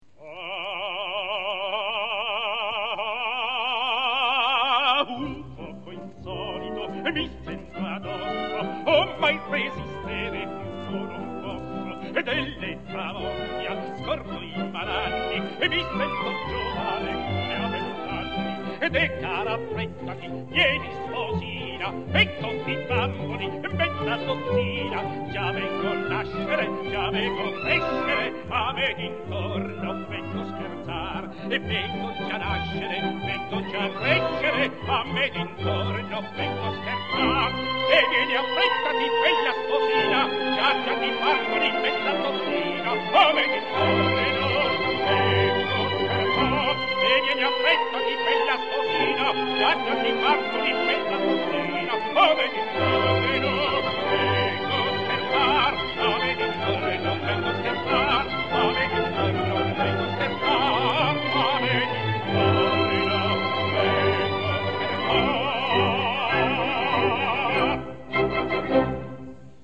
registrazione dal vivo.